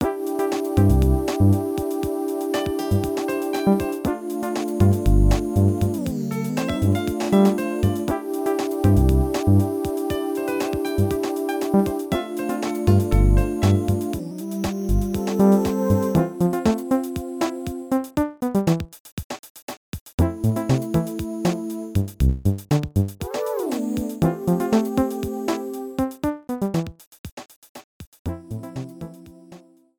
Ripped with Nitro Studio 2
Cropped to 30 seconds, fade out added